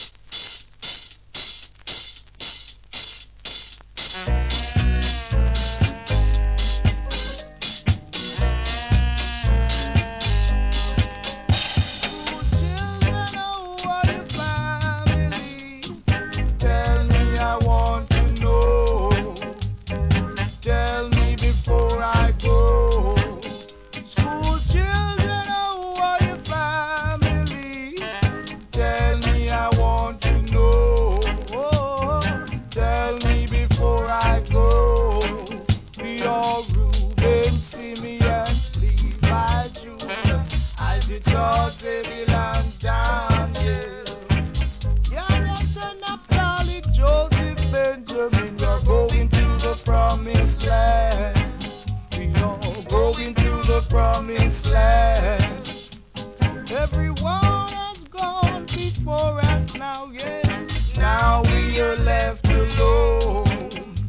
REGGAE
GREAT ROOTS!!